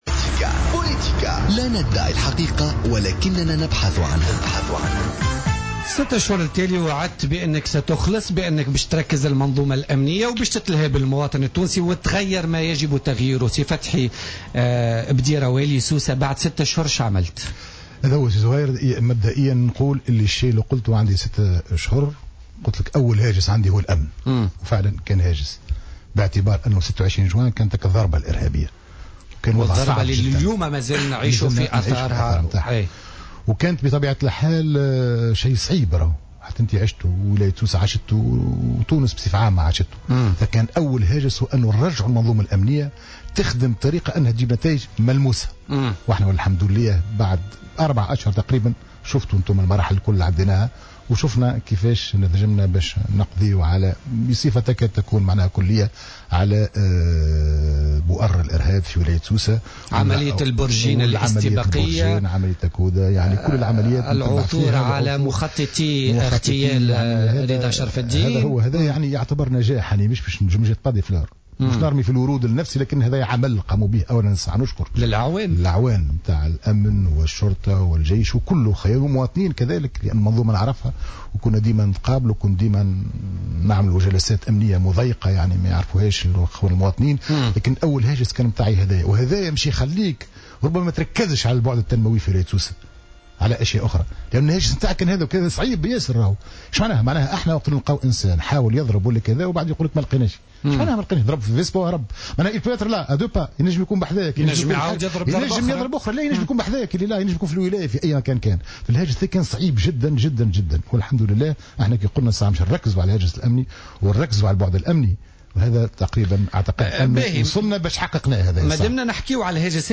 في استوديو "الجوهرة أف أم" : والي سوسة يتفاعل مع مشاغل الأهالي
تفاعل والي سوسة، فتحي بديرة ضيف برنامج "بوليتيكا" اليوم، الثلاثاء مع مشاغل عدد من المواطنين.
وقال الوالي إن الأبواب دائما مفتوحة أمام أهالي ولاية سوسة لطرح مشاغلهم والإصغاء إليهم، نافيا الأخبار التي تم ترويجها بخصوص رفضه مقابلة عدد منهم. وقد تعهّد الوالي بالنظر في بعض الملفات العالقة لبعض المواطنين الذين تدخلوا على المباشر على إذاعة "الجوهرة أف أم".